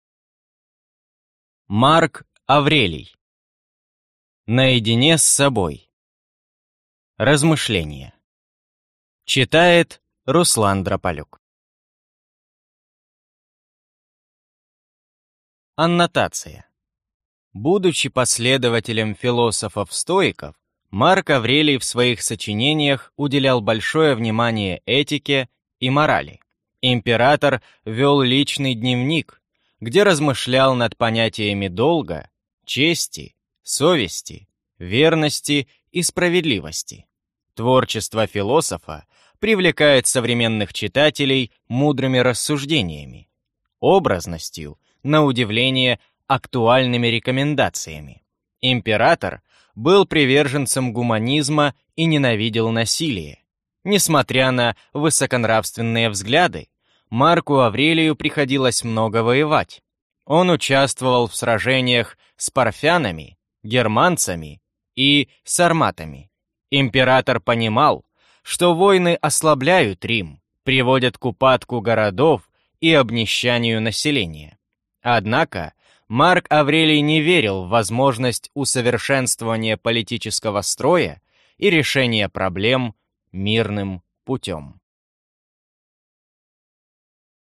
Аудиокнига Весь Марк Аврелий | Библиотека аудиокниг